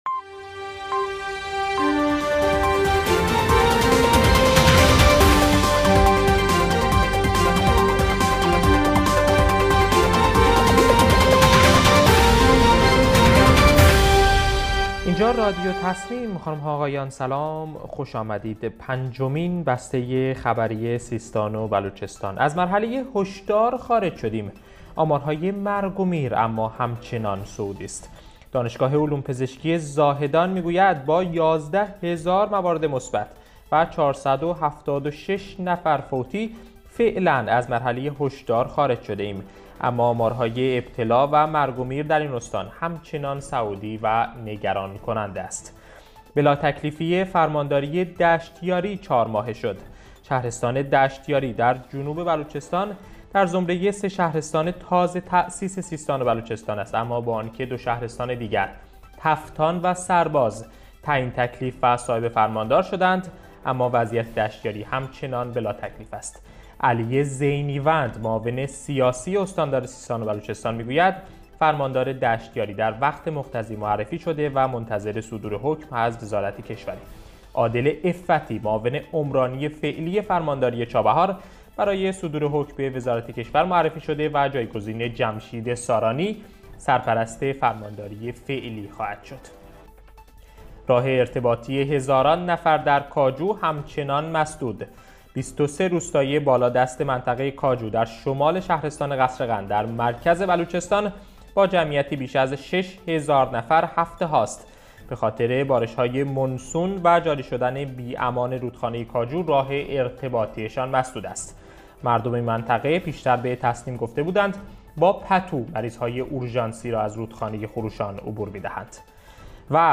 گروه استان‌ها- در پنجمین بسته خبری رادیو تسنیم سیستان و بلوچستان با مهم‌ترین عناوین خبری امروز همراه ما باشید.